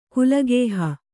♪ kulagēha